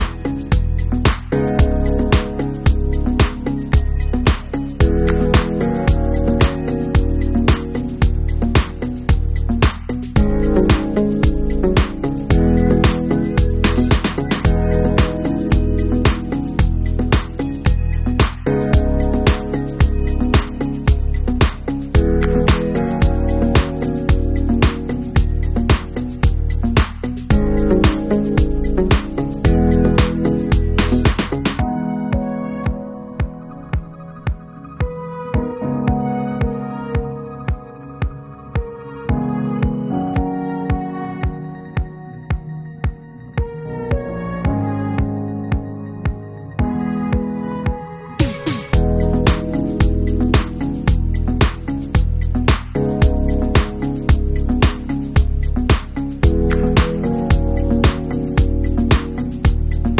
The glitter cardstock was cut with my cricut maker.